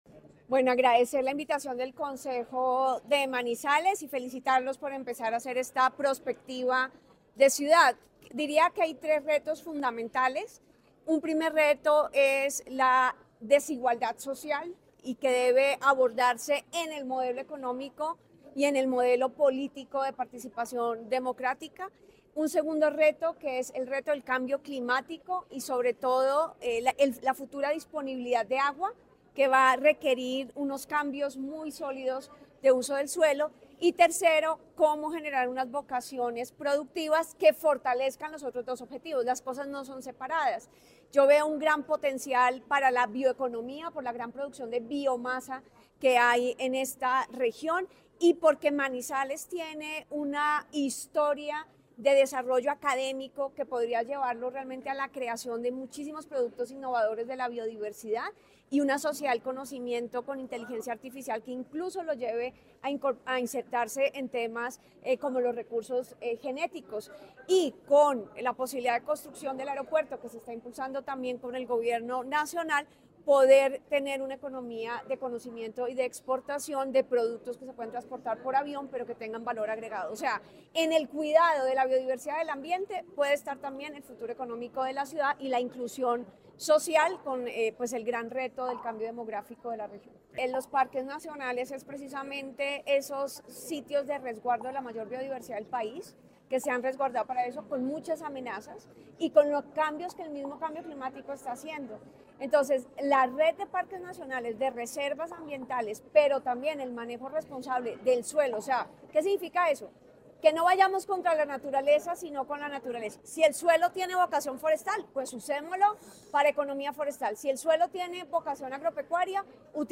Con el objetivo de promover una visión estratégica y prospectiva de Manizales hacia el año 2049, se llevó a cabo el “Foro Bicentenario», un espacio de diálogo abierto para construir colectivamente políticas públicas, proyectos estratégicos y planes de desarrollo sostenible que consoliden a la ciudad como un referente de innovación, competitividad y bienestar en Colombia.
Susana Muhamad, exministra de Ambiente y Desarrollo Sostenible
Full-Susana-Muhamad-exministra-de-Ambiente-y-Desarrollo-Sostenible.mp3